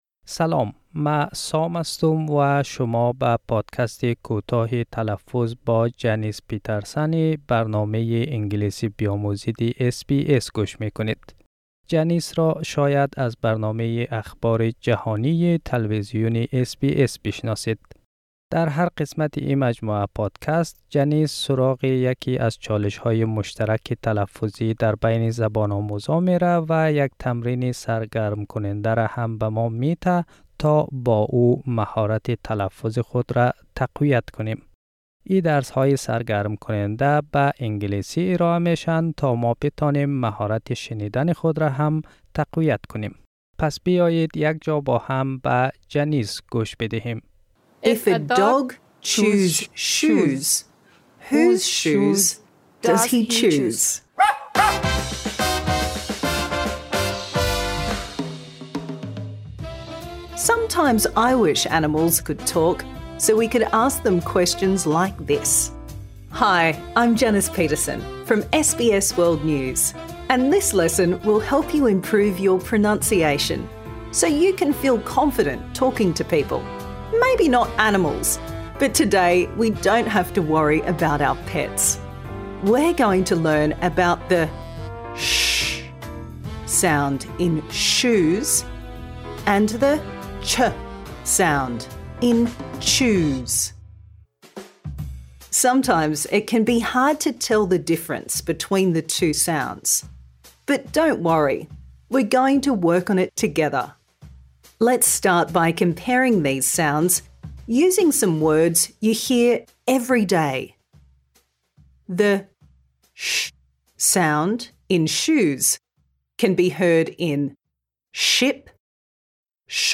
در این قسمت، نحوه تلفظ /tʃ/ و /ʃ/ (ch و sh) را یاد می‌گیرید.